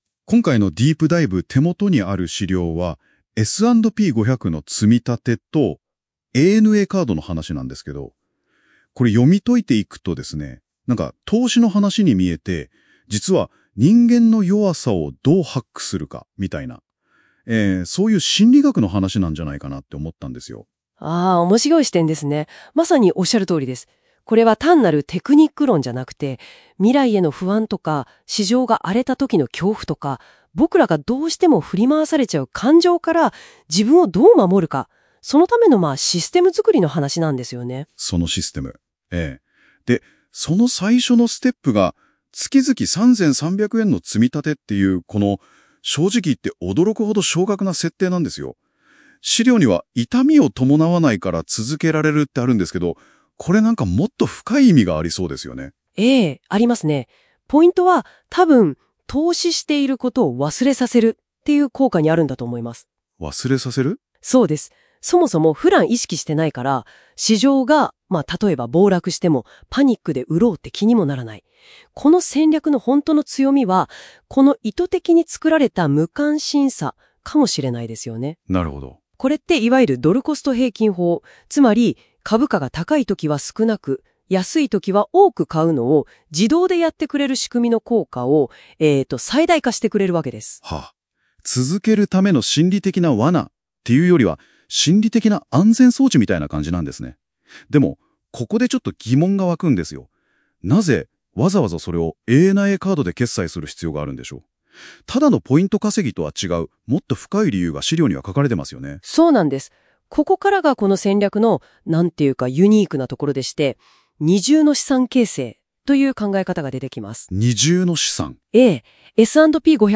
【音声解説】S&P500とANAカード人間の感情ハック術